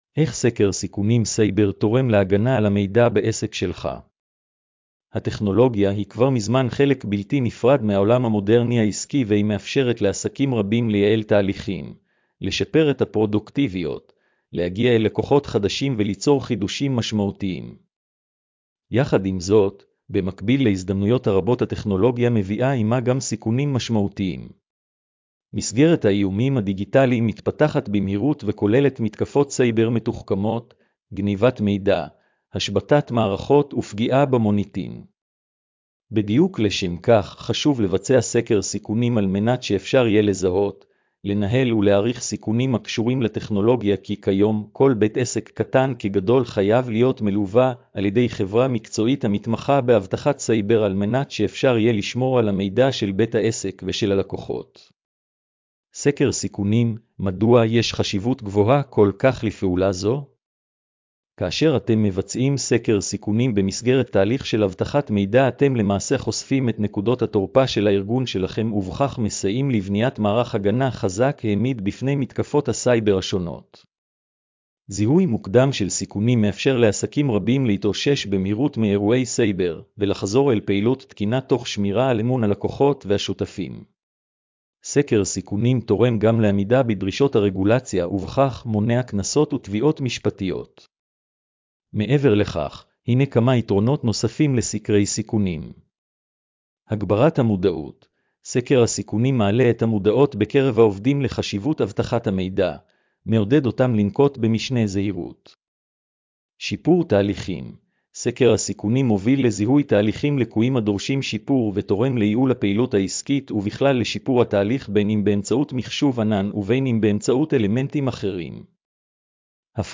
הקראת המאמר לבעלי מוגבלות: